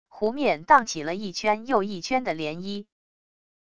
湖面荡起了一圈又一圈的涟漪wav音频生成系统WAV Audio Player